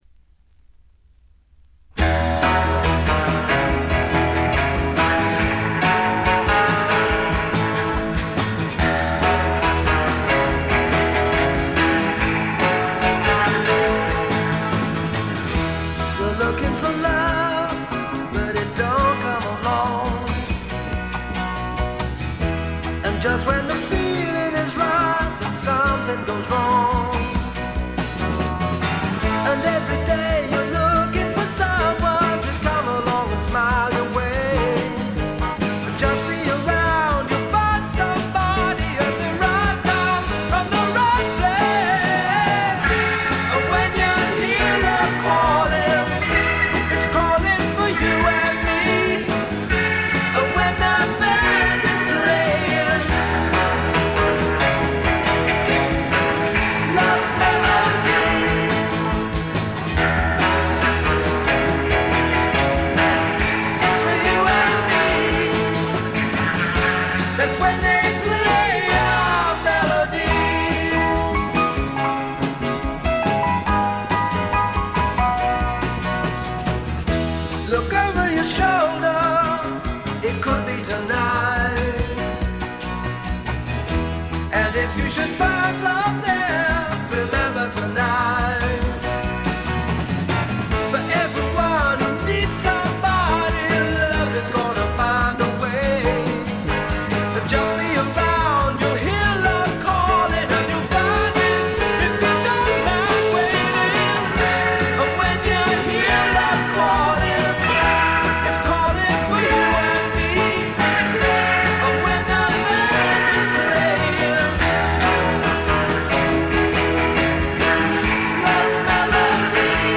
ごくわずかなノイズ感のみ。
ほのかない香るカントリー風味に、何よりも小粋でポップでごきげんなロックンロールが最高です！
試聴曲は現品からの取り込み音源です。
Rockfield Studios, South Wales